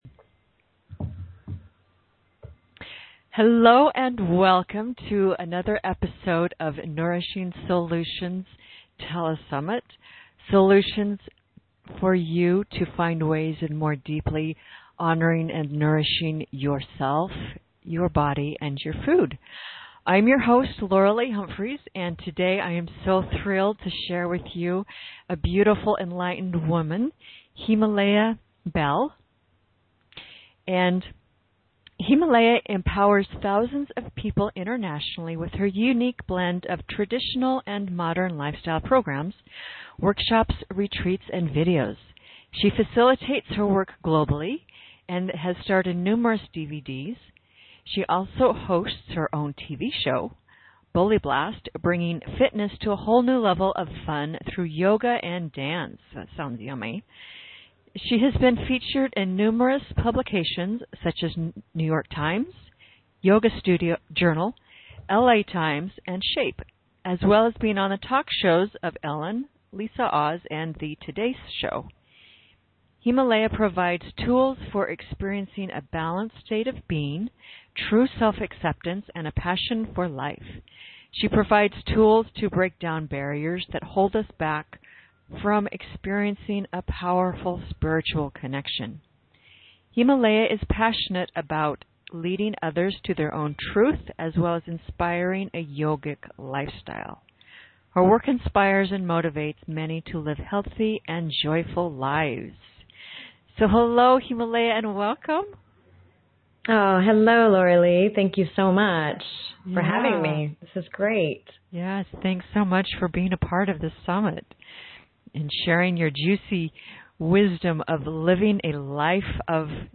I share some valuable exercises with you in this podcast interview.